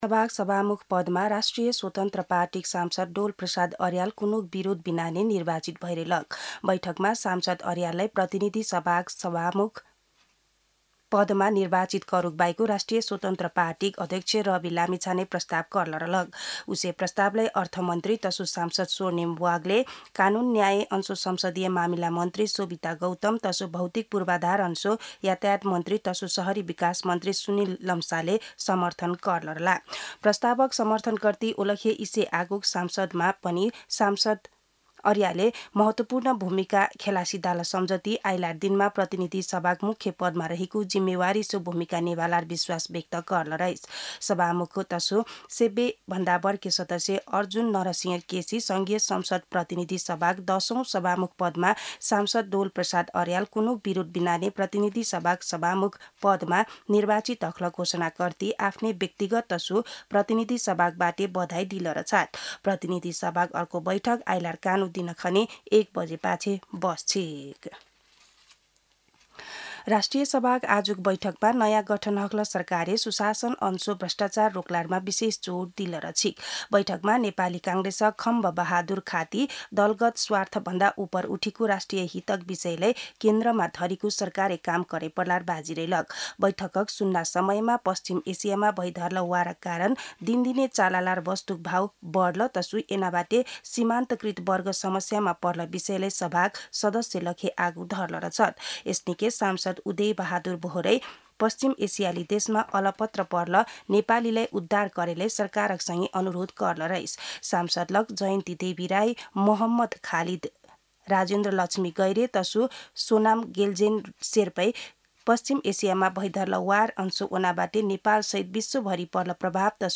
दनुवार भाषामा समाचार : २२ चैत , २०८२